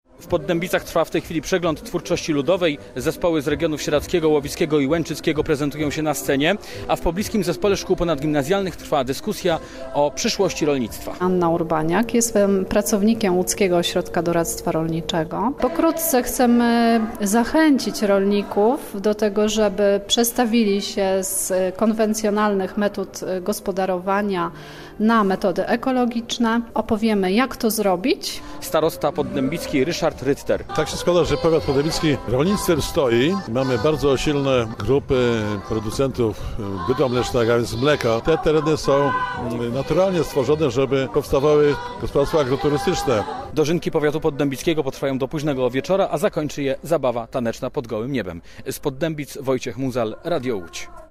Na miejscu stanęło plenerowe studio Radia Łódź. Nazwa Plik Autor Dożynki Powiatu Poddębickiego 2017 audio (m4a) audio (oga) Warto przeczytać Pieniądze na sport w województwie łódzkim. 12 obiektów przejdzie remont 9 lipca 2025 Niż genueński w Łódzkiem.